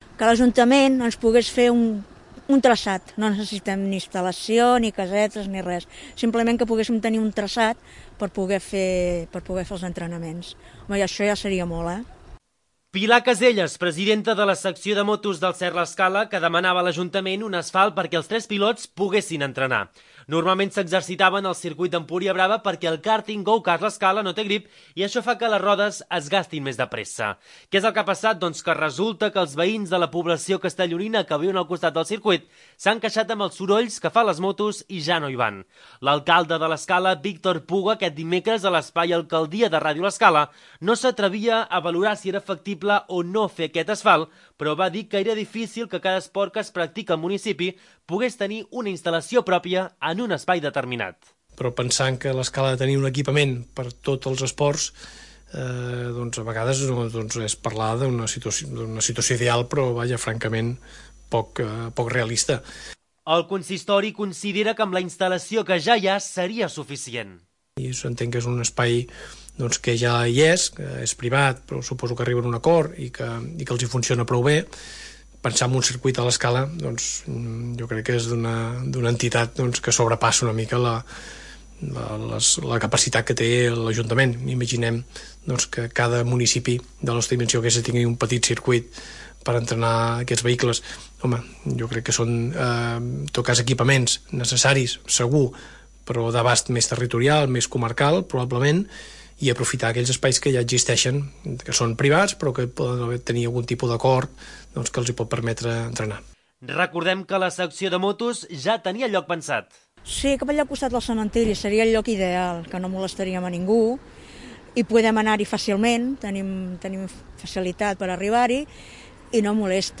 L'alcalde de l'Escala, Víctor Puga, aquest dimecres a l'espai Alcaldia de Ràdio l'Escala, no s'atrevia a valorar si era factible o no fer aquest asfalt, però va dir que era difícil que cada esport que es practica al municipi pogués tenir una instal·lació pròpia en un espai determinat.